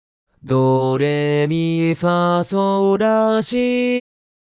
【説明】 ： 最も平坦で平均的なパフォーマンス
強くも弱くもない、いい塩梅の無難な音源です